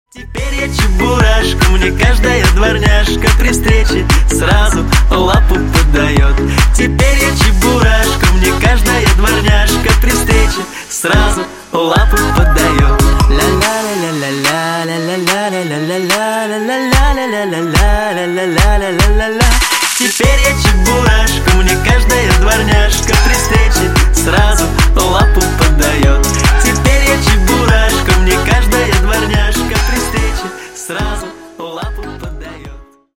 Весёлые Рингтоны
Поп Рингтоны